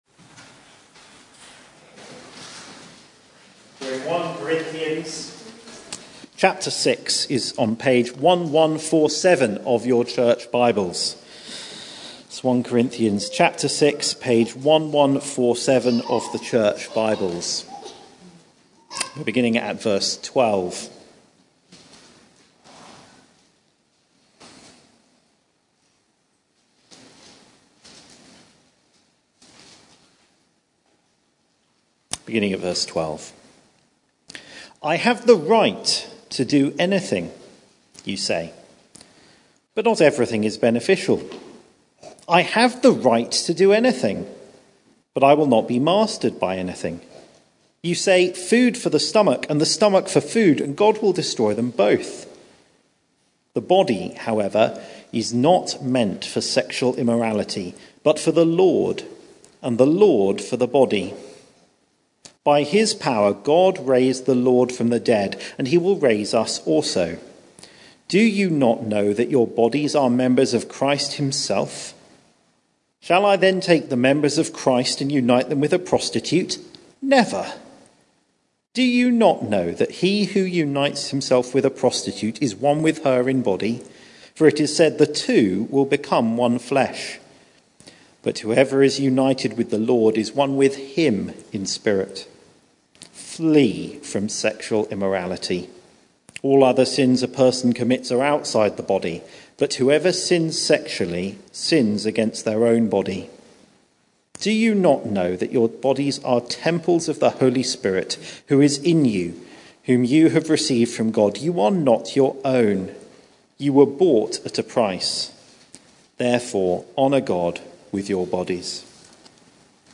Media for Church at the Green Sunday 4pm
Theme: Sermon